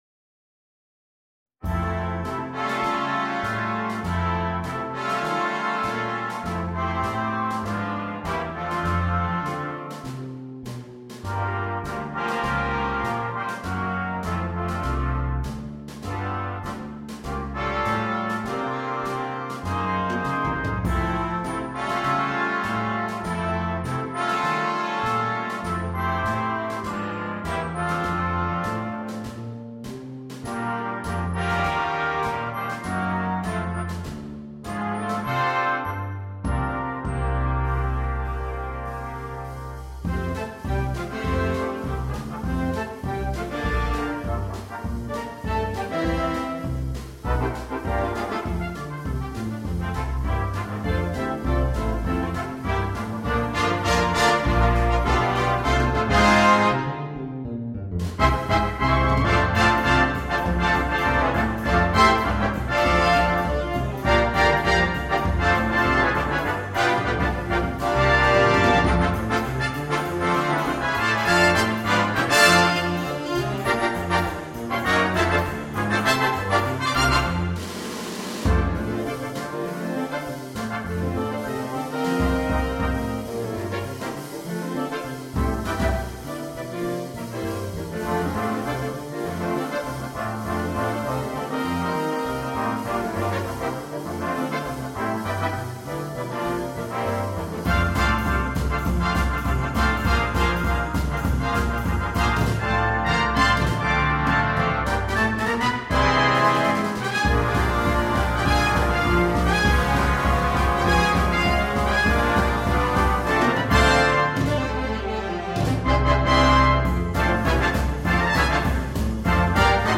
на духовой оркестр